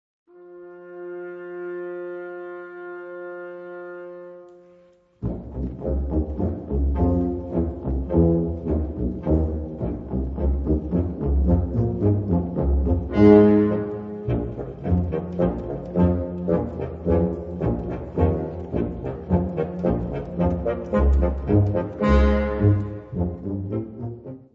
Gattung: Suite
Besetzung: Blasorchester
zeitgemäßen Bearbeitung für Sinfonisches Blasorchester.